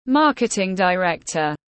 Giám đốc truyền thông tiếng anh gọi là marketing director, phiên âm tiếng anh đọc là /ˈmɑː.kɪ.tɪŋ daɪˈrek.tər/.
Marketing director /ˈmɑː.kɪ.tɪŋ daɪˈrek.tər/